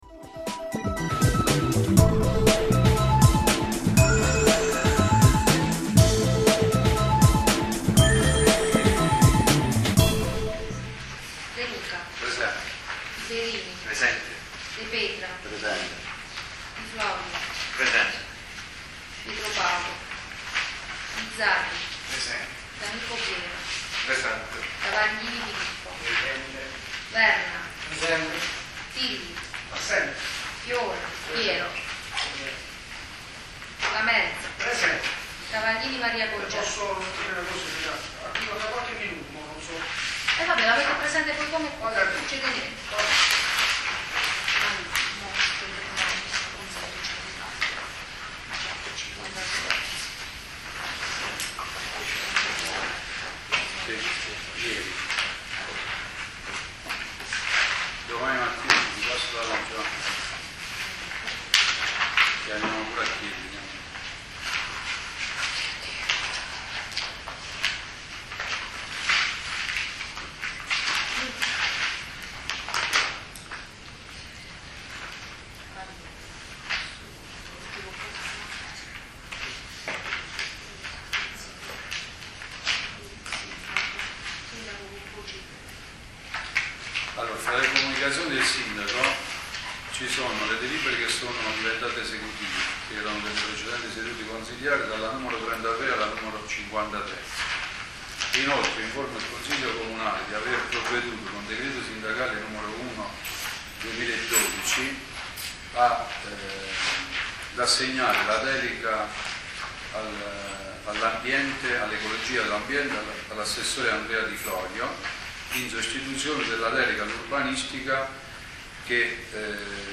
Ascolta il Consiglio Comunale del 26 Aprile 2012
Espone l'assessore Domenico De Petra . Intervengono il consigliere di minoranza Vincenzo Lamelza , il consigliere di maggioranza Filippo Travaglini e il Sindaco Sergio De Luca .